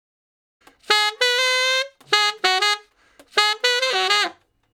068 Ten Sax Straight (Ab) 24.wav